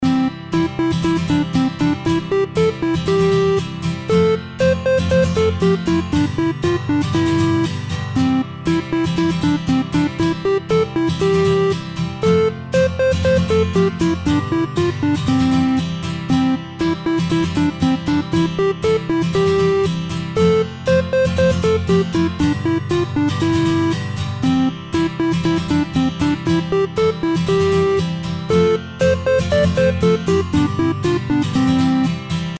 LOOP推奨
楽曲の曲調： SOFT